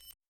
pixel-slide.wav